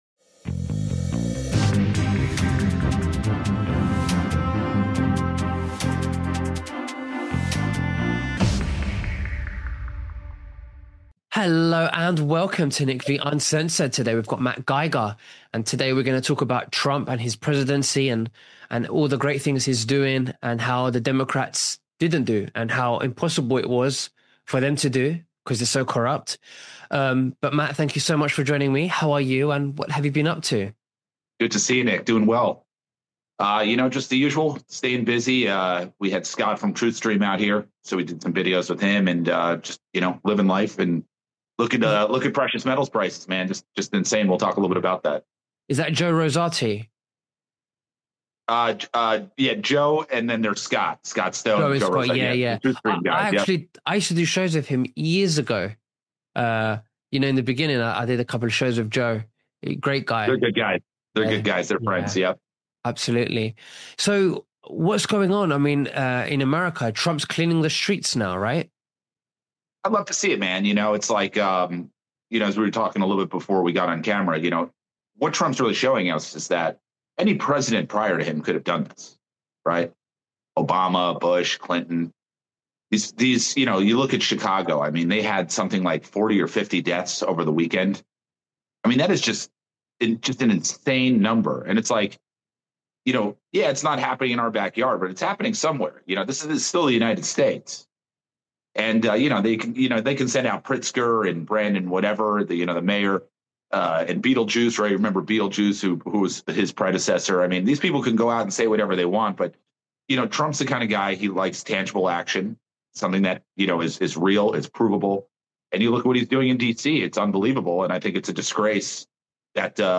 🎙 Live Shows